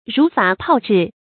如法泡制 rú fǎ pào zhì
如法泡制发音
成语注音 ㄖㄨˊ ㄈㄚˇ ㄆㄠˋ ㄓㄧˋ